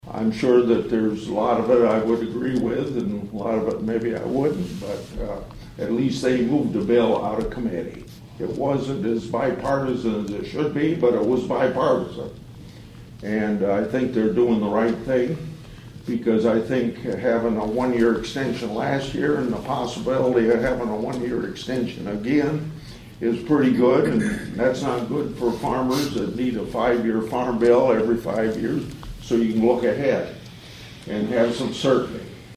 (Atlantic) Senator Chuck Grassley held a town hall meeting at the Iowa Western Community College Cass County campus in Atlantic this (Tuesday) morning.